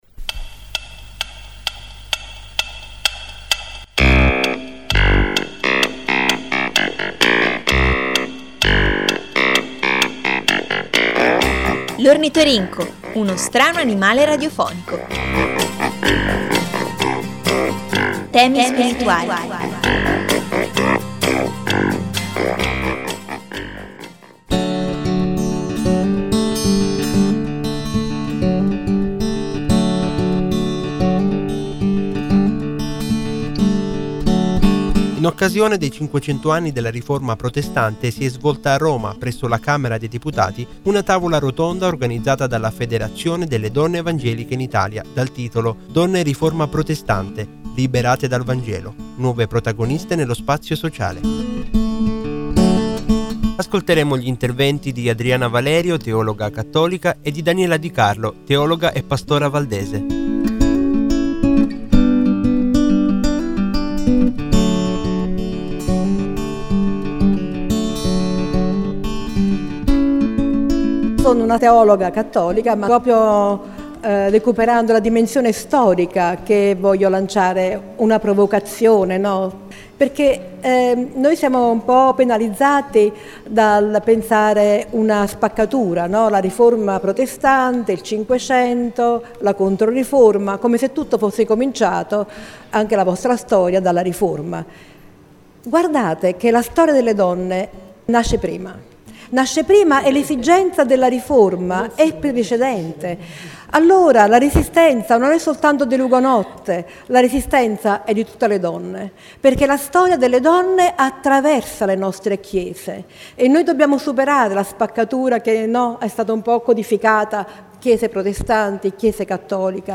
A Roma, presso la Camera dei Deputati, si è tenuta una tavola rotonda dedicata alle donne di fede evangelica impegnate nella società.